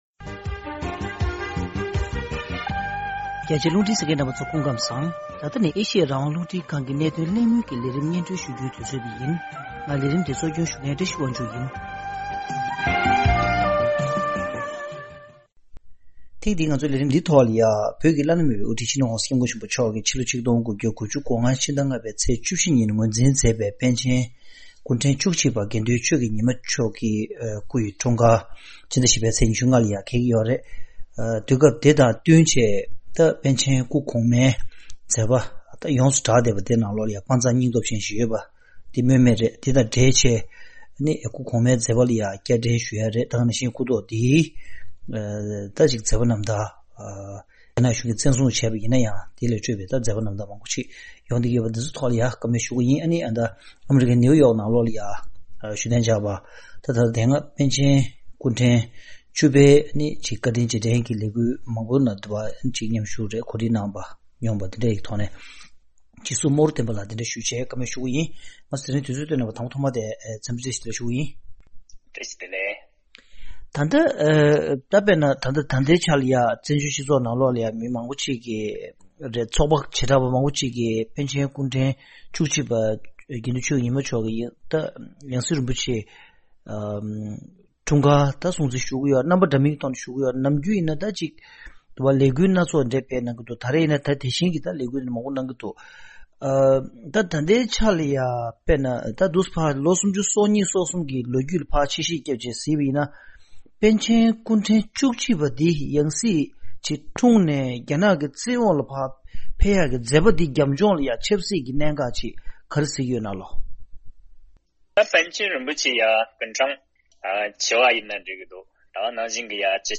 པཎ་ཆེན་དགེ་འདུན་ཆོས་ཀྱི་ཉི་མ་མཆོག་དགུང་གྲངས་༣༣ལ་ཕེབས་པའི་སྐུའི་འཁྲུངས་སྐར་དང་འབྲེལ་པཎ་ཆེན་སྐུ་གོང་མའི་མཛད་པ་སྐོར་གླེང་མོལ་ཞུས་པ།